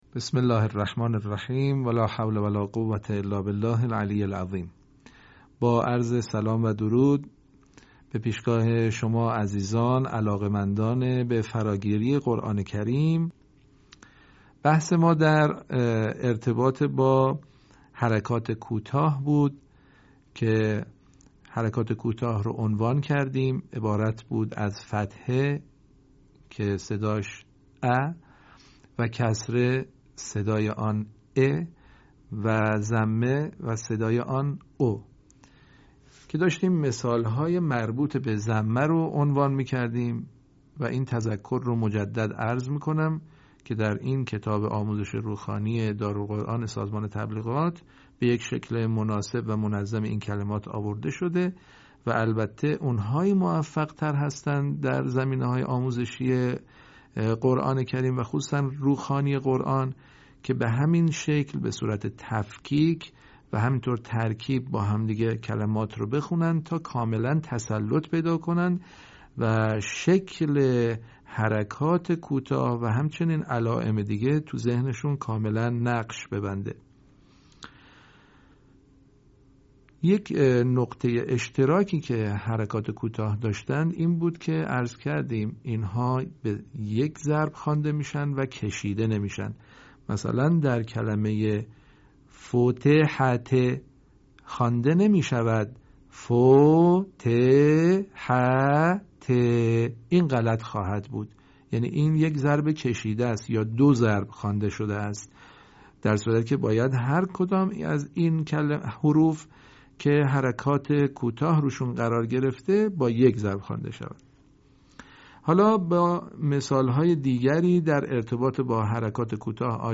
صوت | آموزش حرکات کوتاه در روخوانی قرآن کریم